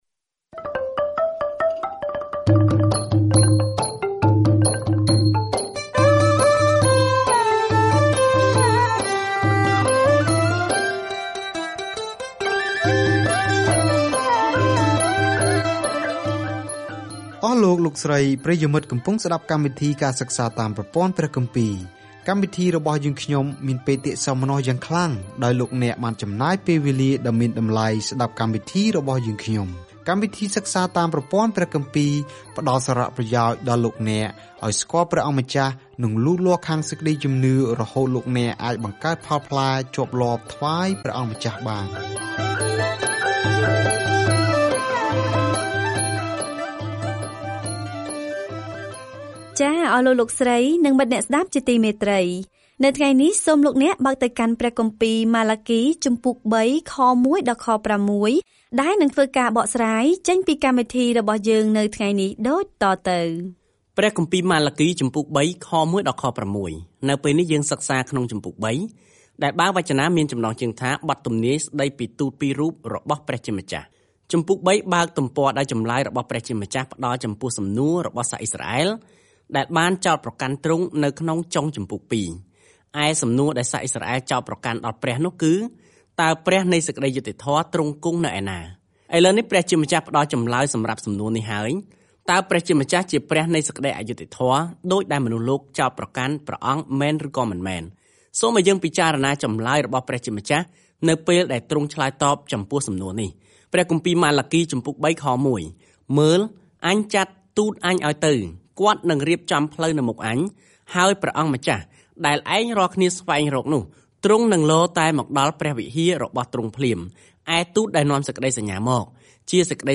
ម៉ាឡាគីរំឭកជនជាតិអ៊ីស្រាអែលដែលផ្តាច់ទំនាក់ទំនងថាគាត់មានសារពីព្រះមុនពេលពួកគេស៊ូទ្រាំនឹងភាពស្ងៀមស្ងាត់ដ៏យូរ - ដែលនឹងបញ្ចប់នៅពេលដែលព្រះយេស៊ូវគ្រីស្ទចូលដល់ឆាក។ ការធ្វើដំណើរជារៀងរាល់ថ្ងៃតាមរយៈម៉ាឡាគី នៅពេលអ្នកស្តាប់ការសិក្សាជាសំឡេង ហើយអានខគម្ពីរដែលជ្រើសរើសពីព្រះបន្ទូលរបស់ព្រះ។